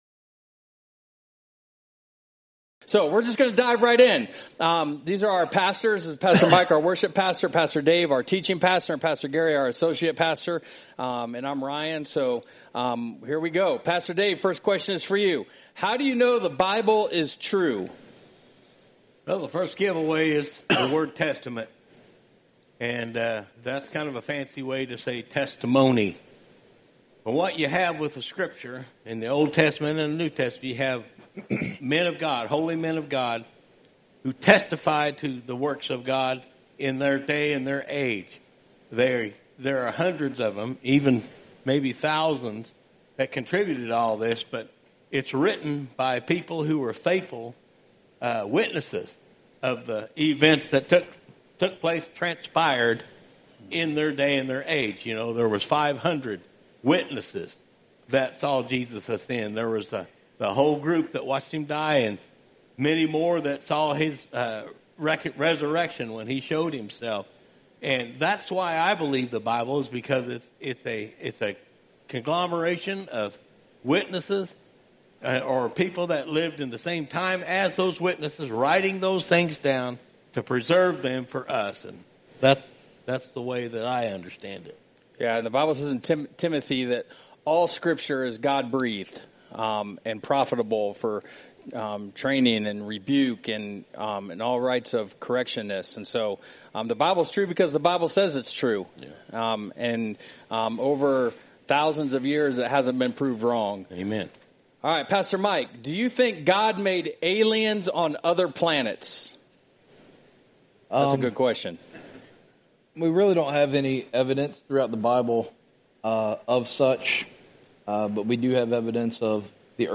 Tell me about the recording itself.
Q&A-9:30 Service (11/9/25)